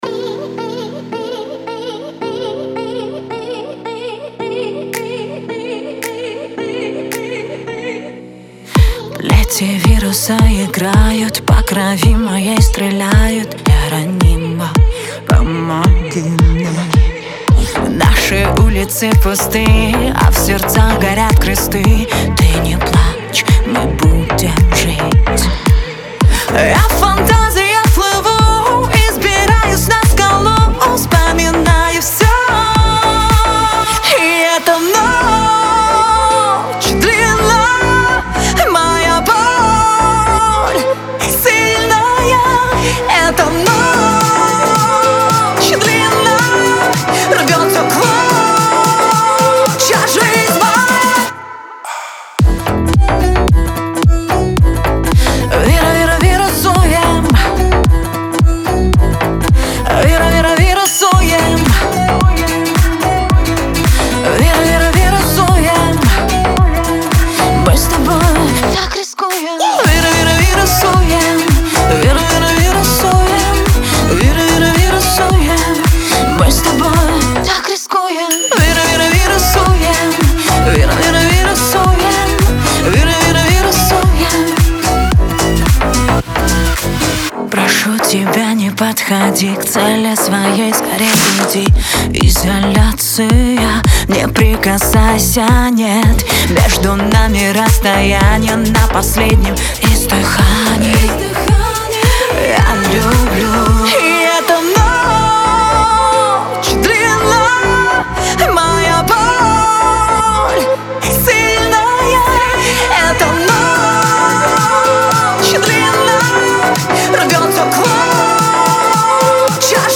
это яркая и энергичная композиция